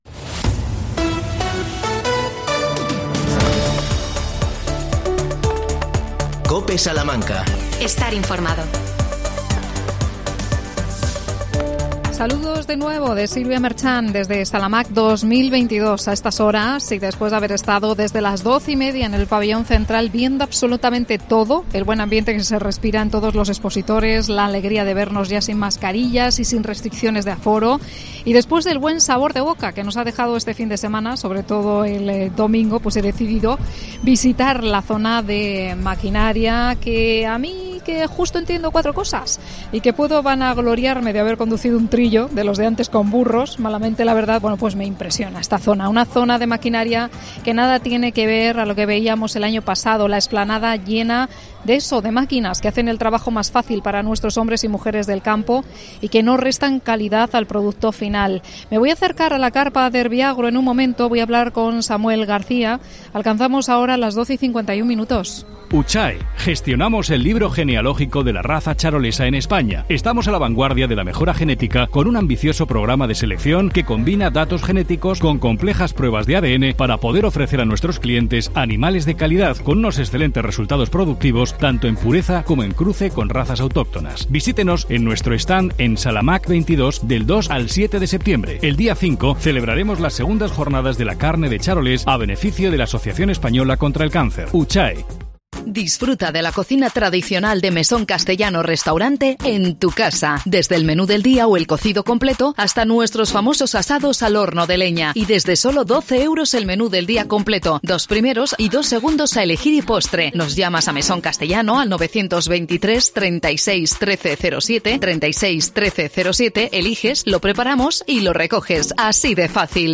AUDIO: Programa especial desde la feria Agromaq 2022 (3ª parte)